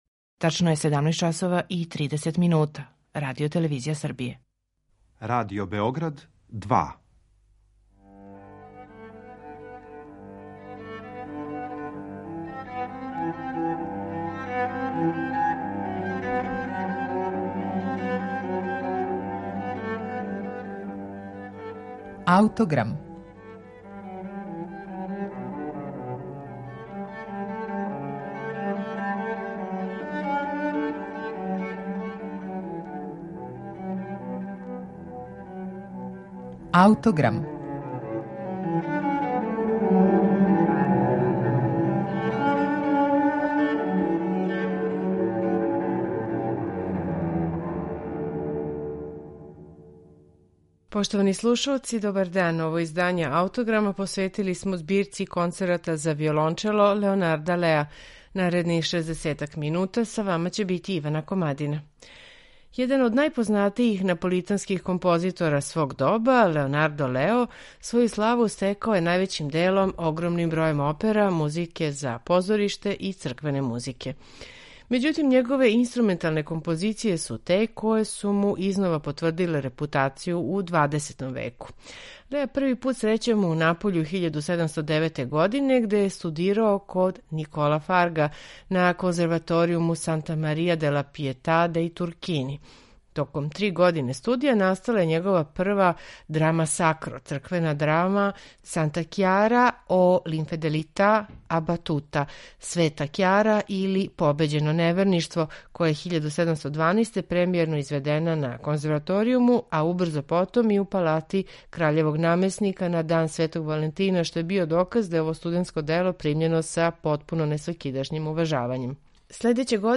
Велики део заслуге за то припада његовој збирци шест концерата за виолончело и оркестар којој смо посветили данашњи Аутограм. Године 1737, када су ови концерти настали, избор виолончела као солистичког инстурмента био је више него изненађујући.